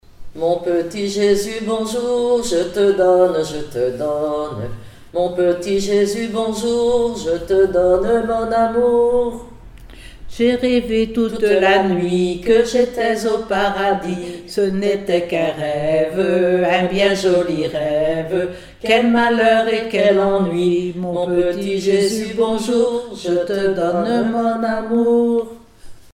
Île-d'Yeu (L')
enfantine : berceuse
Comptines et formulettes enfantines